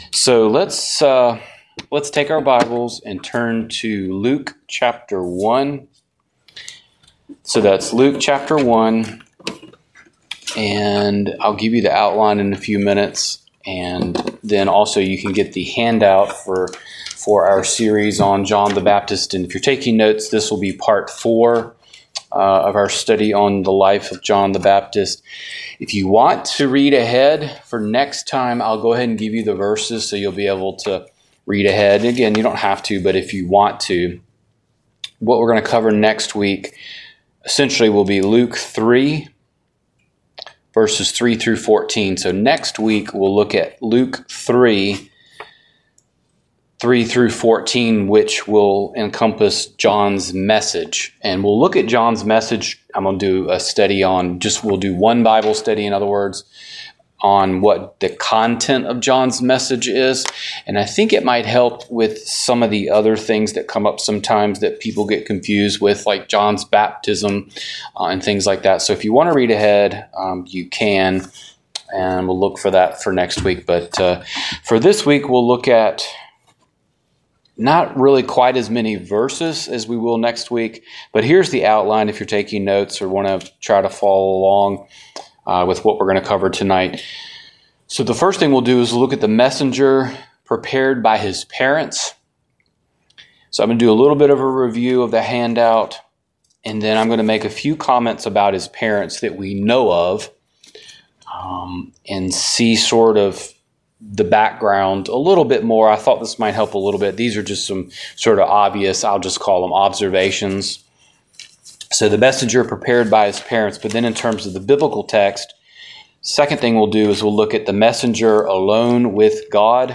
Download Download The Life of John the Baptist - Part 4 Wed. Night Bible Study Matthew 23:37-39 "The King's Lament" Wed.